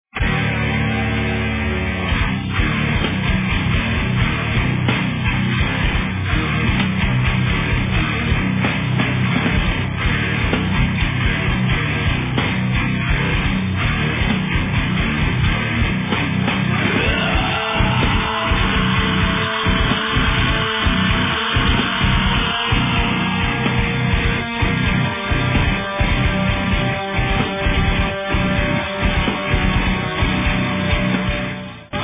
Металл.